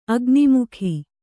♪ agnimukhi